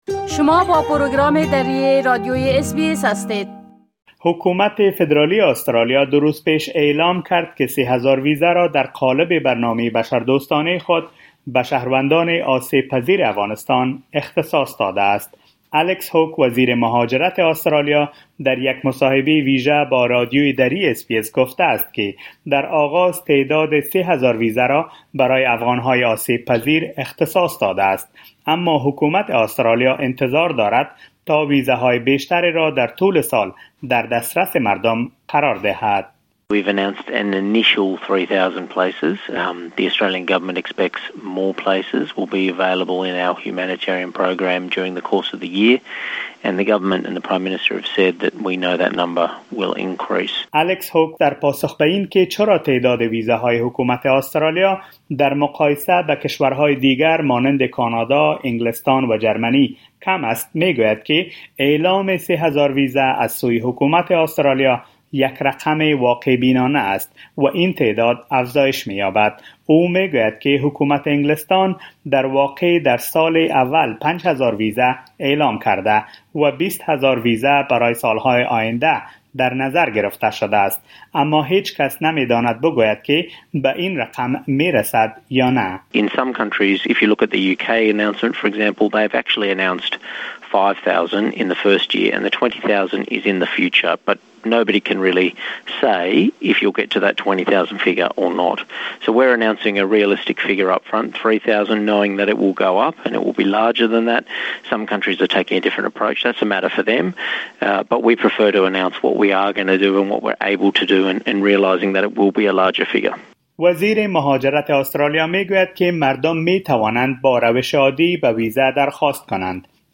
الیکس هاوک وزیر مهاجرت آسترالیا در یک مصاحبه ویژه با رادیو دری اس بی اس گفته است که در آغاز تعداد ۳۰۰۰ ویزه را برای افغانهای آسیب پذیر اختصاص داده است، اما حکومت آسترالیا انتظار دارد تا ویزه های بیشتری را در طول سال در دسترس مردم قرار دهد.